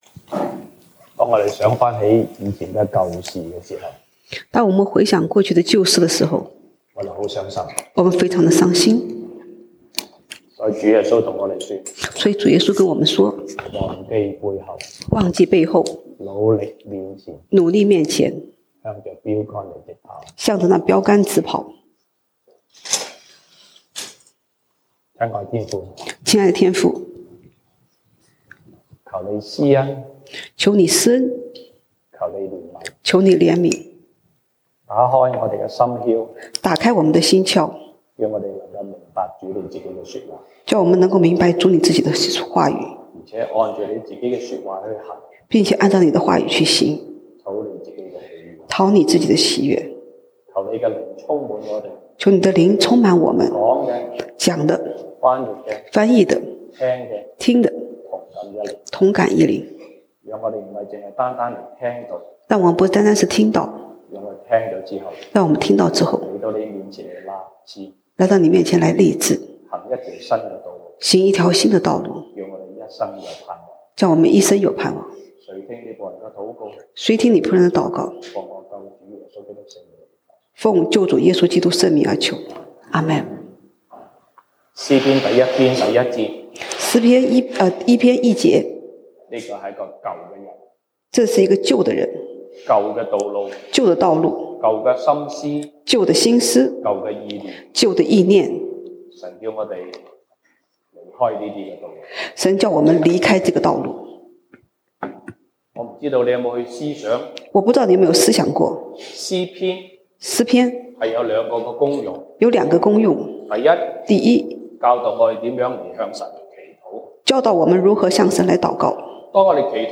西堂證道(粵語/國語) Sunday Service Chinese: 新人，新事，新盼望